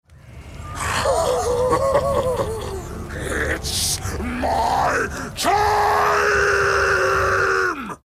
Download Donation Alert -Warhammer Sound effect Button free on sound buttons.
stream_alertdonation.mp3